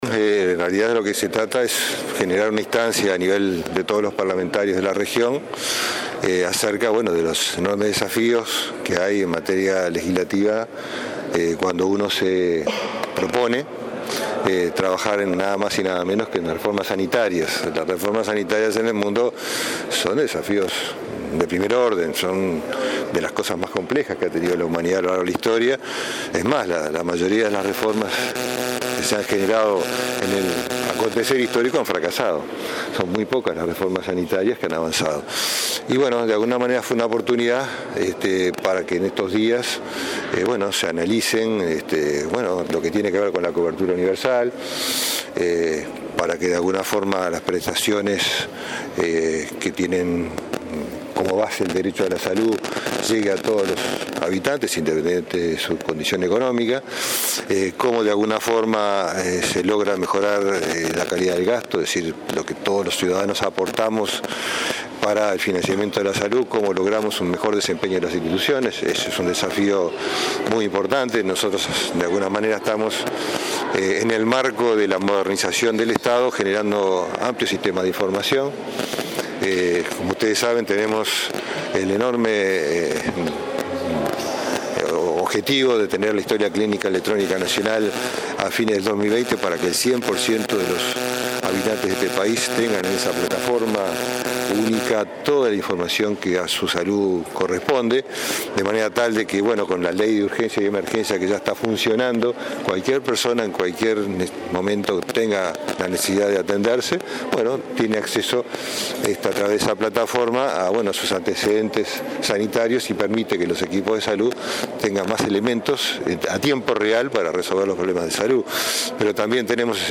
Al participar del IV Congreso de Comisiones de Salud de los Parlamentarios de las Américas, este lunes en Montevideo, repasó las mejoras instrumentadas por el ministerio. Destacó la puesta en marcha del sistema de información sobre los servicios que otorgan los prestadores.